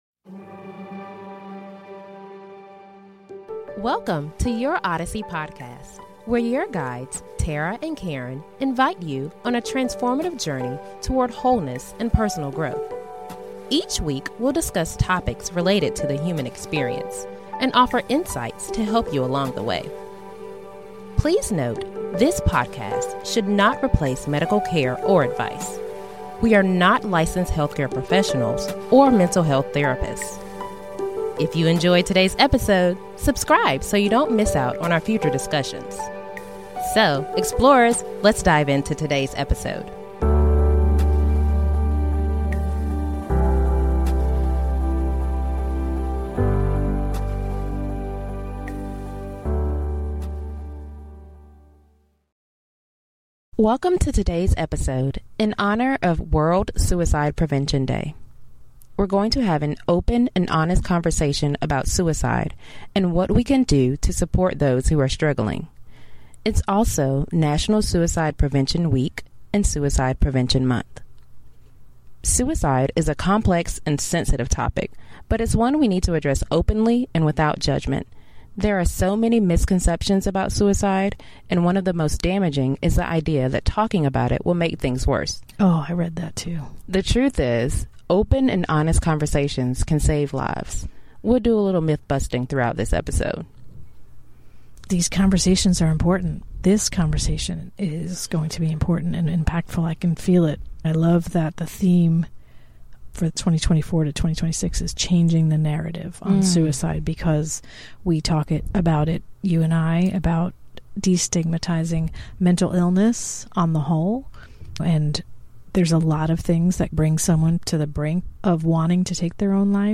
In this special episode in honor of World Suicide Prevention Day, we have an open and honest conversation about suicide, aiming to break the stigma and ensure that everyone feels supported. In this episode, we explore the history, statistics, and myths surrounding suicide while also providing insights into the factors contributing to suicidal thoughts and behaviors.